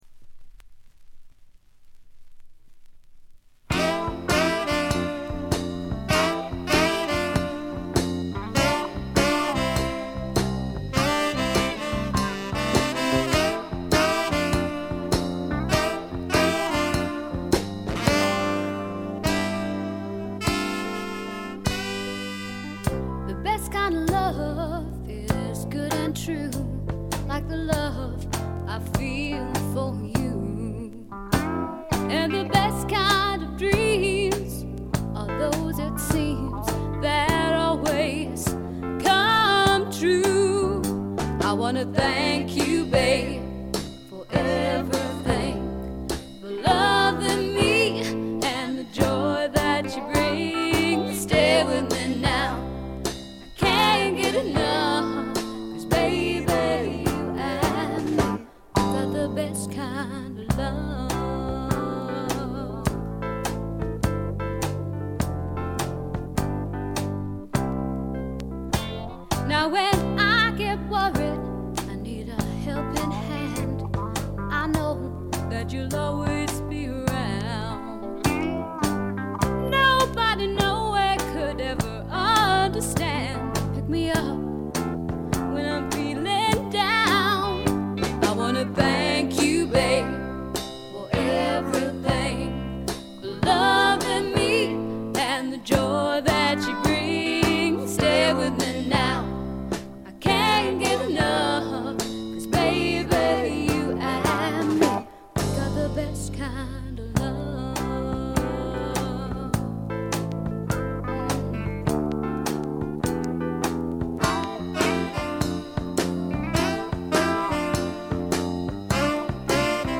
イングランドの小さなレーベルに残された女性シンガーの好盤です。
試聴曲は現品からの取り込み音源です。